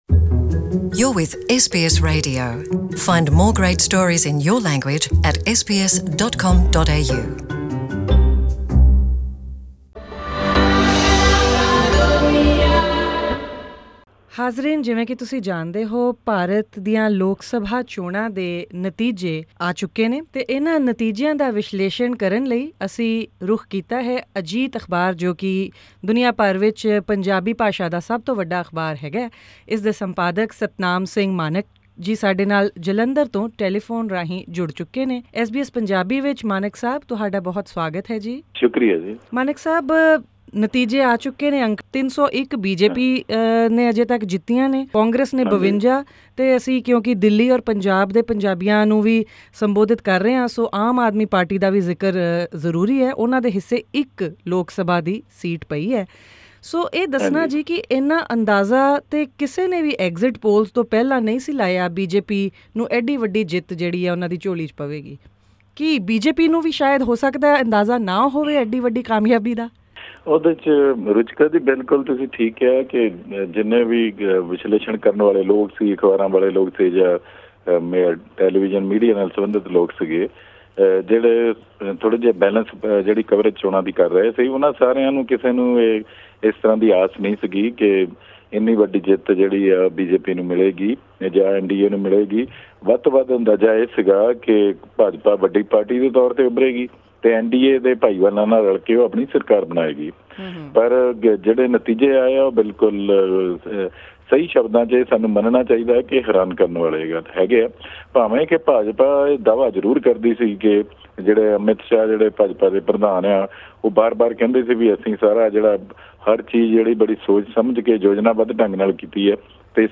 SKIP ADVERTISEMENT Click on the player at the top to listen to this interview in Punjabi.